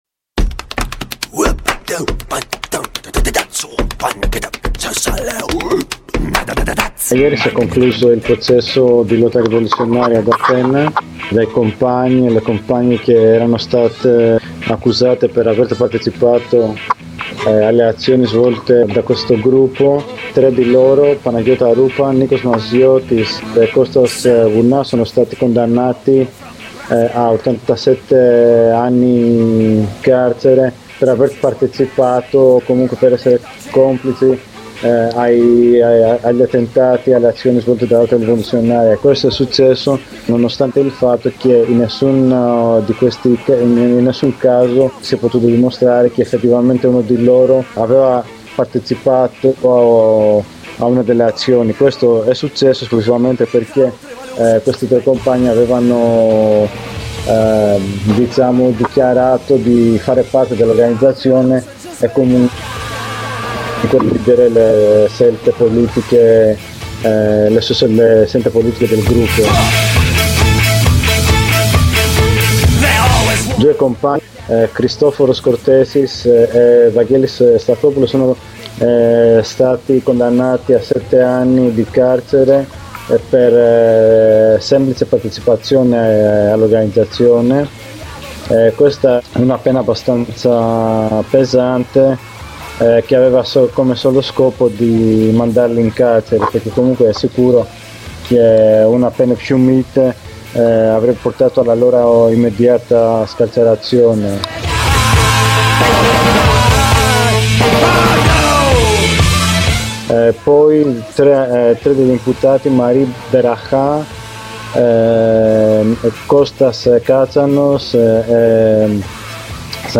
Su questo esito processuale abbiamo chiesto ad un compagno di Atene un commento a caldo.